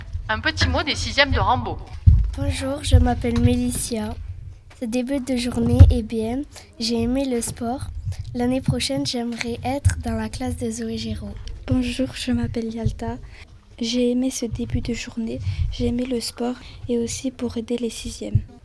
• En éducation musicale, 200 élèves ont relevé le défi de la chorale